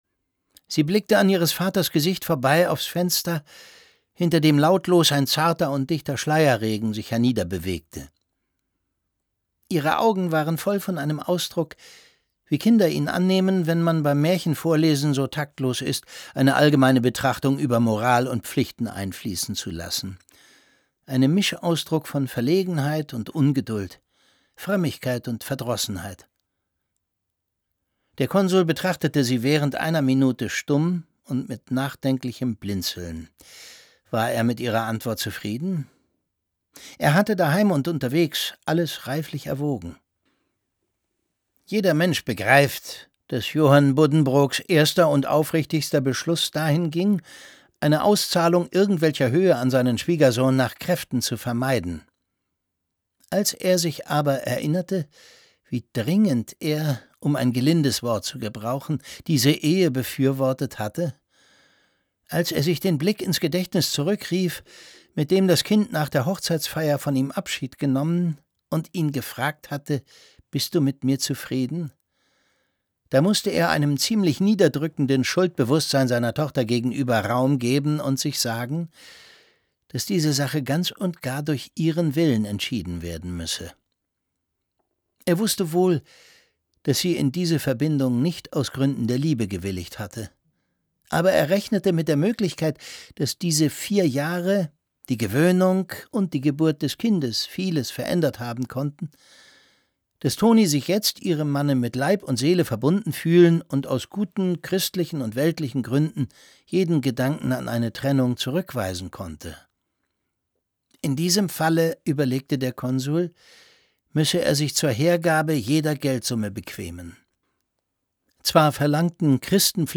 Es liest Thomas Sarbacher.